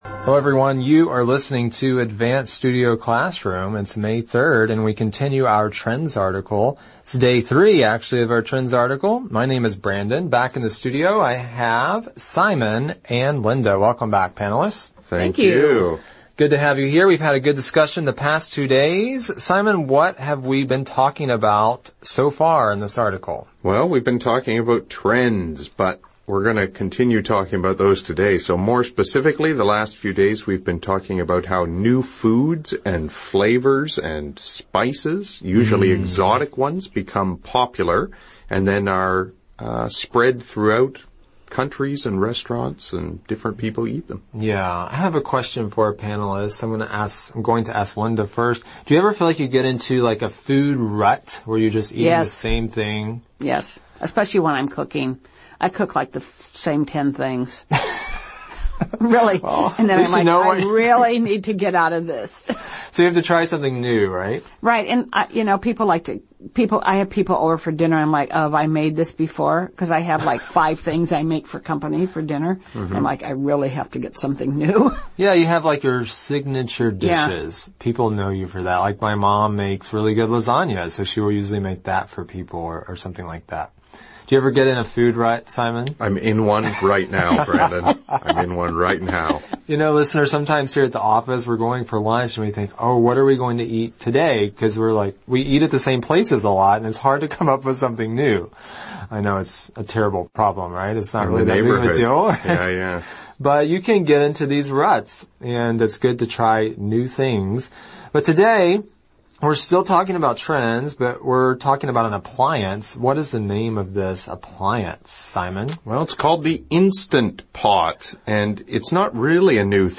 《空中英语教室》主要以收录世界最新时尚资讯、热点话题、人物、故事、文化、社会现象等为主，以谈话聊天类型为主的英语教学节目。